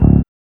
4308R BASS.wav